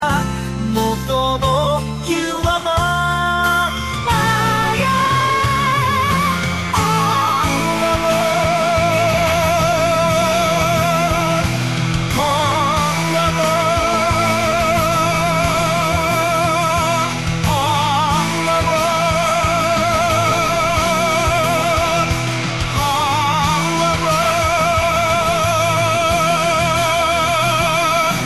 クソみたいな歌詞を書いたからかもしれませんが、 クソみたいな曲が出来上がりました。